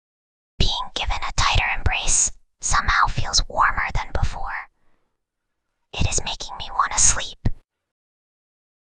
Whispering_Girl_35.mp3